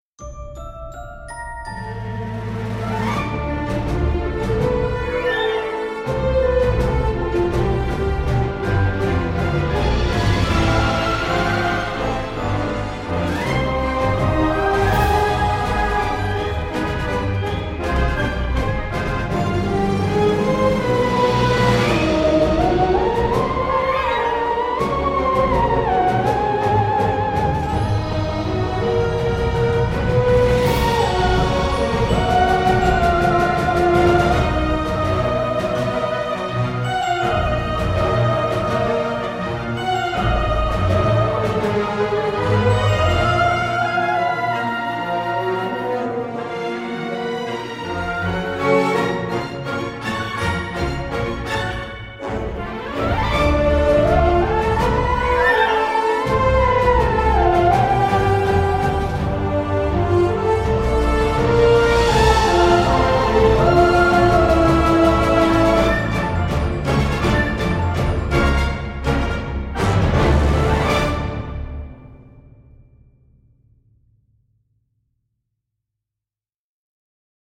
Ici, tout est donc forcément plus virevoltant et exalté.
Un peu générique, voire passe-partout